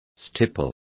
Complete with pronunciation of the translation of stipples.